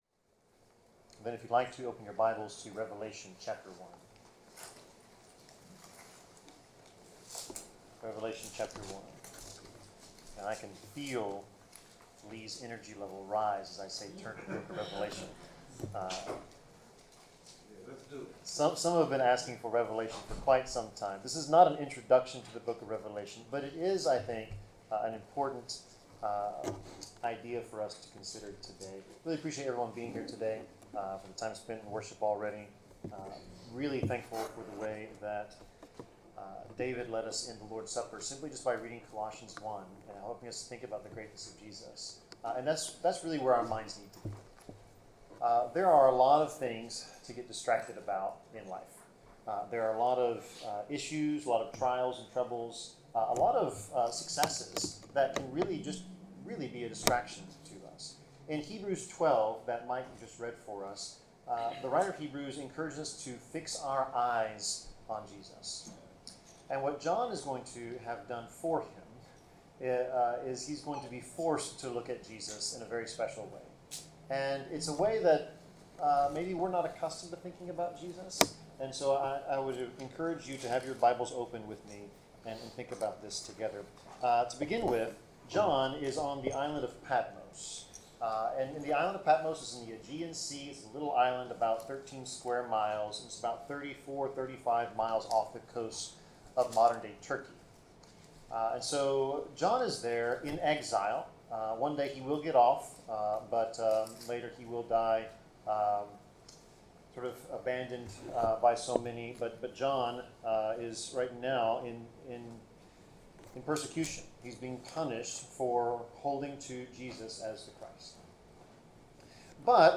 Service Type: Sermon